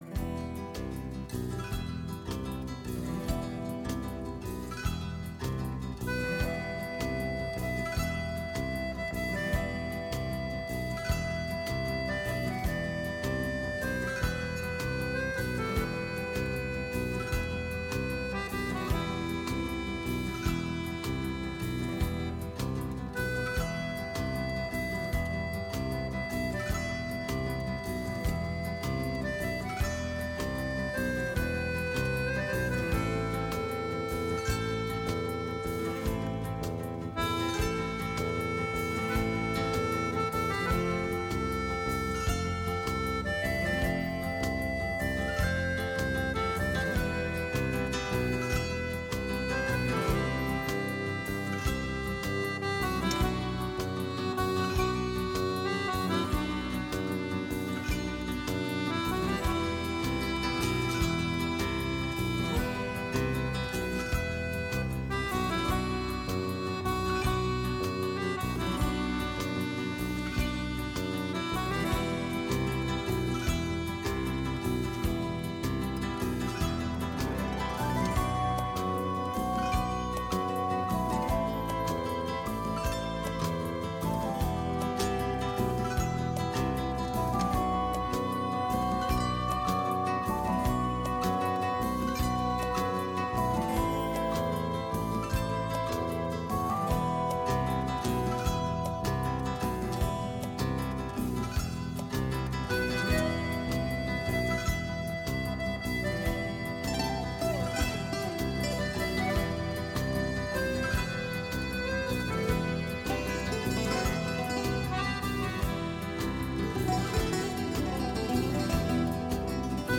ούτι
ένα μαγικό ταξίδι στο χώρο του παραδοσιακού αλλά και του έντεχνου τραγουδιού